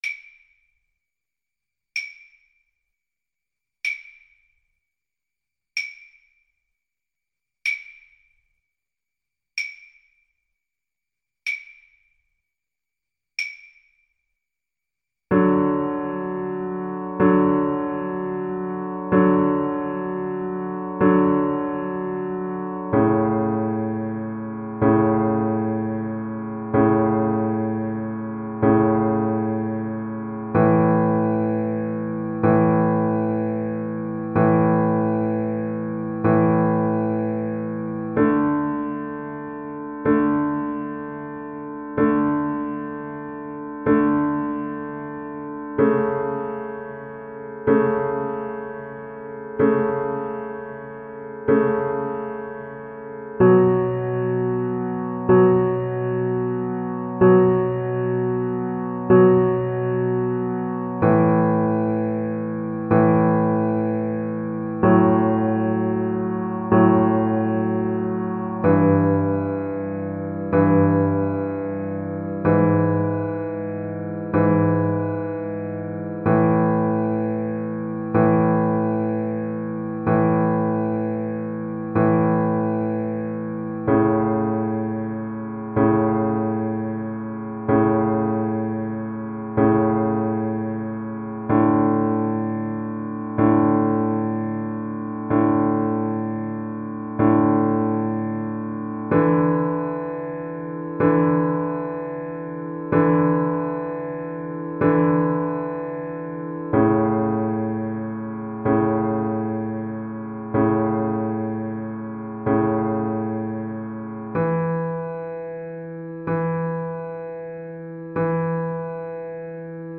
Largo
Classical (View more Classical Viola Music)